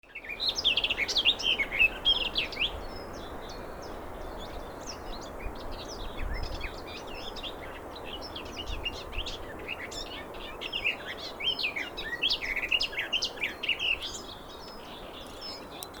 Птицы -> Славковые ->
садовая славка, Sylvia borin